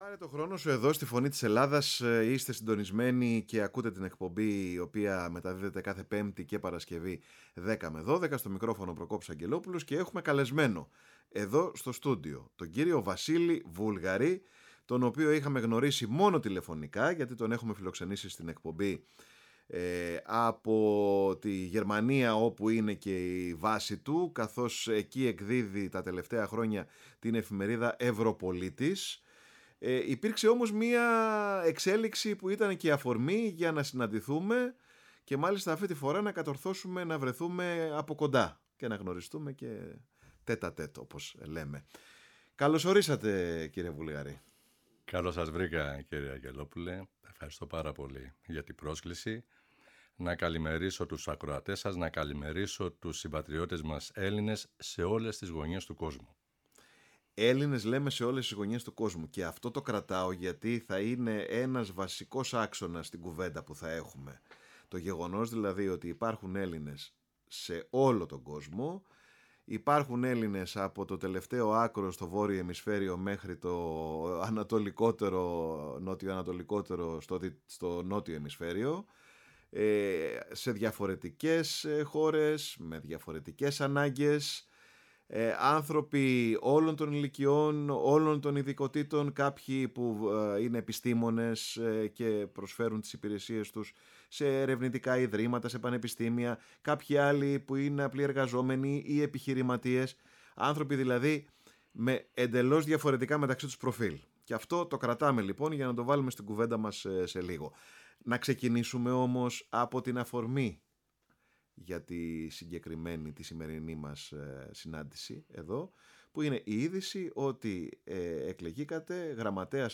στο στούντιο της Φωνής της Ελλάδας, στην εκπομπή «Πάρε τον Χρόνο σου»